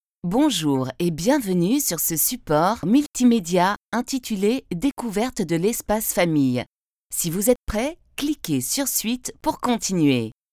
Voice over depuis 1988, je peux aussi bien enregistrer des messages sur un ton jeune et dynamique que grave et posé.
Kein Dialekt
Sprechprobe: eLearning (Muttersprache):